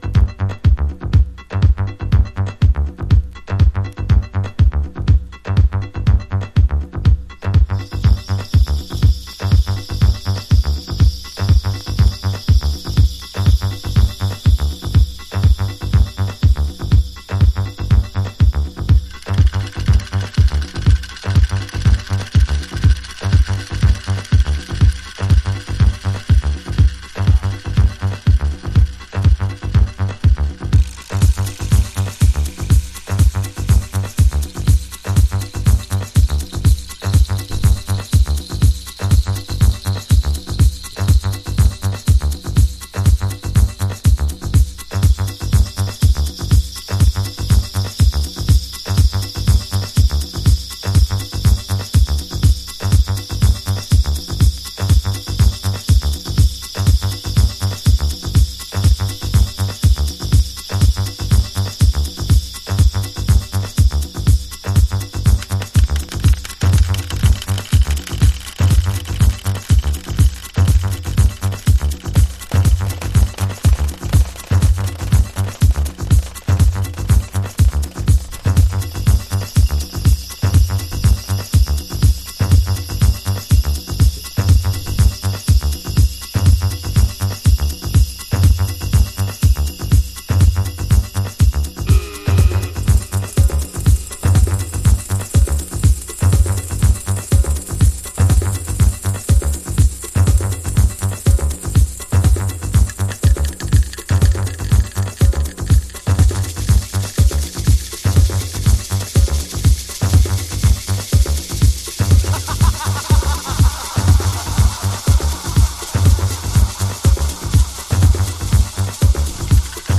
Early House / 90's Techno
オリジナルは92年のリリース、90's Deep House Classic。
RAWなSEのトバしっぷりとか、グッときます。